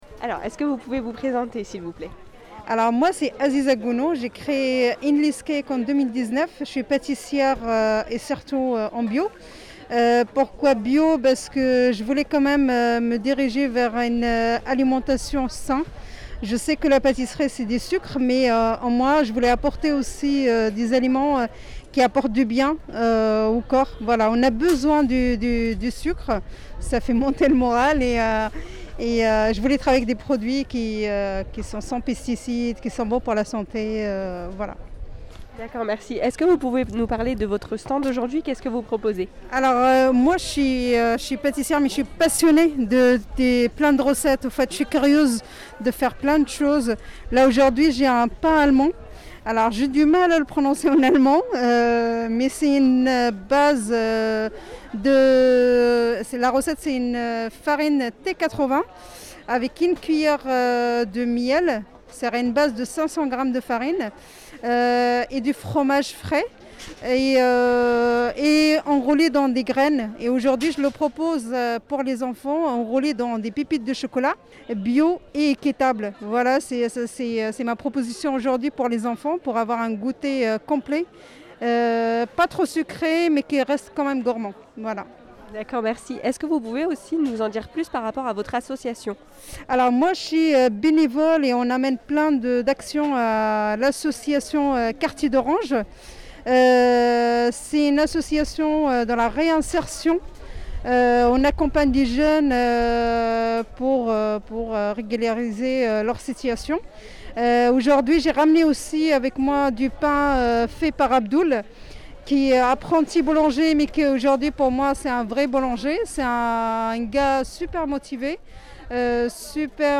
PATISSIER Interview
AU MARCHE PAYSAN